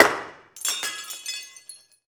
Index of /90_sSampleCDs/Roland - Rhythm Section/PRC_Guns & Glass/PRC_Glass Tuned